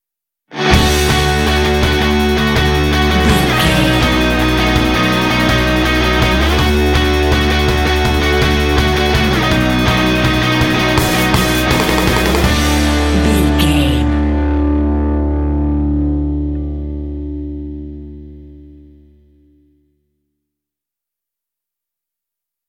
Ionian/Major
Fast
driving
energetic
lively
electric guitar
drums
bass guitar
classic rock
alternative rock